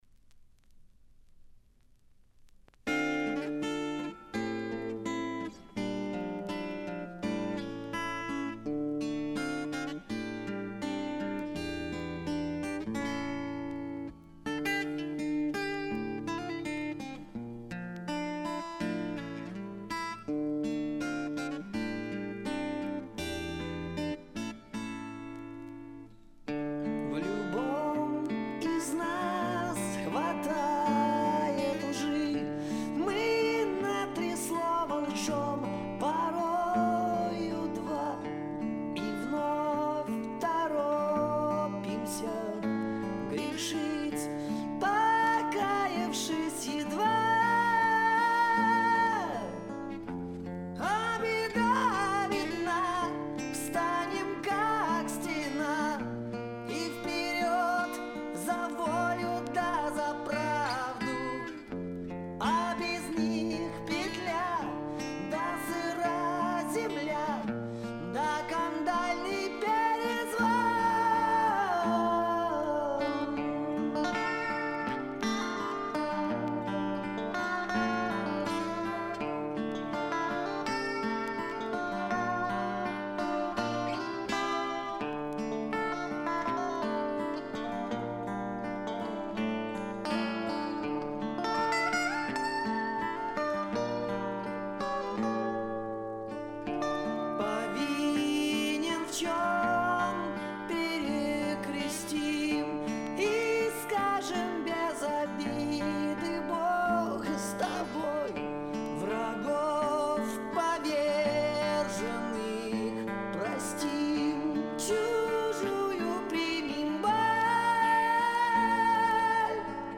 Жанр: Rock
Стиль: Soft Rock, Hard Rock